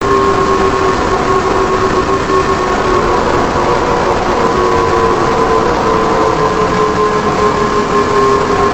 Ambushscream.mp3